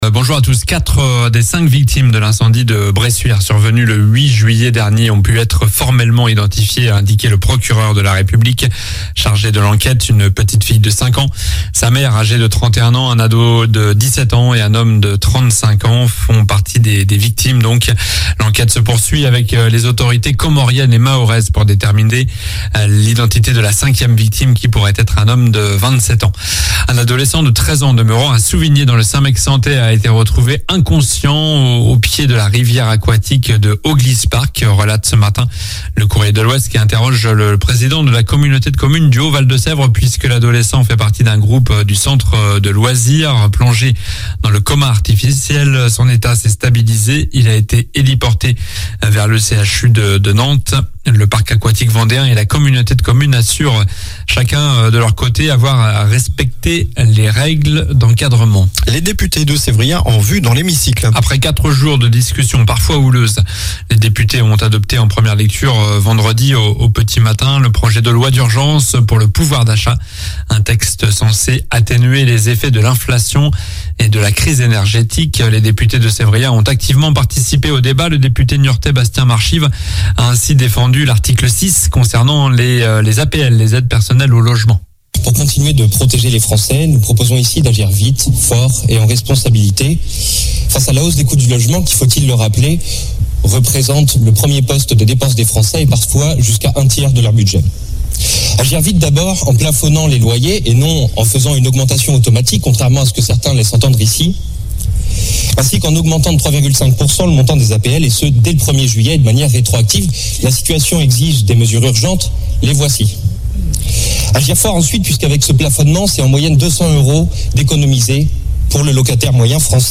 Journal du samedi 23 juillet (matin)